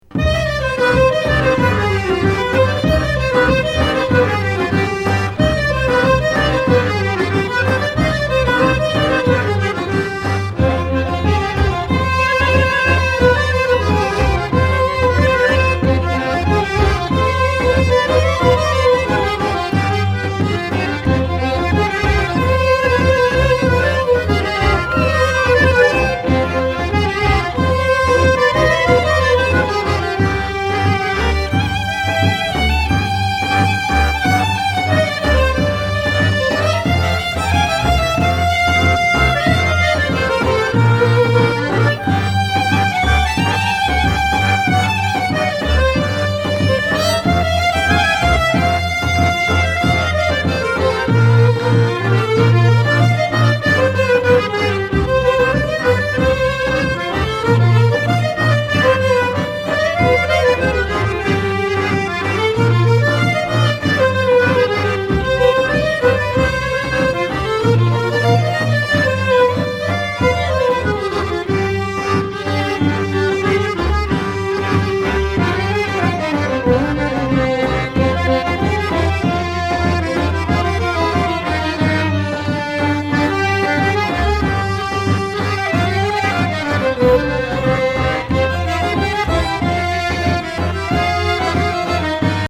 Tsimbl, 19th Century Button Accordion
Three-string Bratsch (Viola), Baraban (Drum)
Klezmer — Instrumental Jewish Music from Eastern Europe.
Genre: Klezmer.